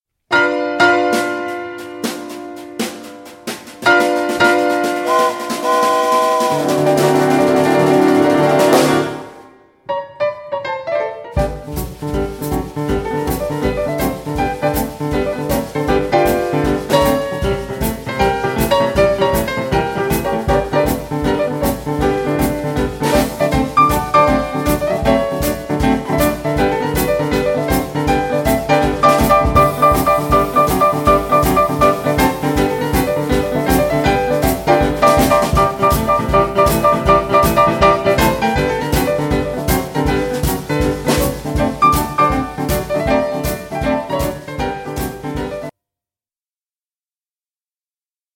Smart & Swing / Blues & Boogie woogie